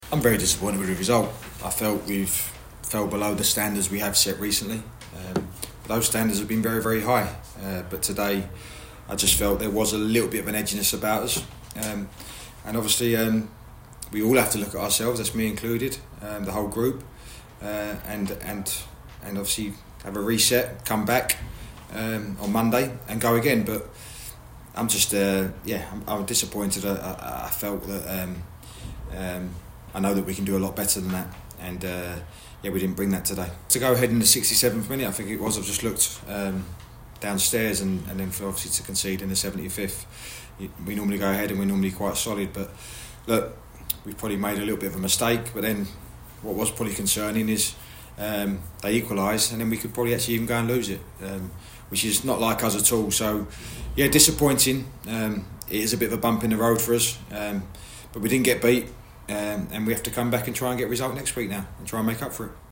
LISTEN: Gillingham manager Stephen Clemence speaks of his disappointment at 1-1 draw with Forest Green Rovers 21/01/24